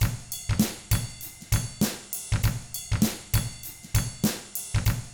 99FUNKY4T3-L.wav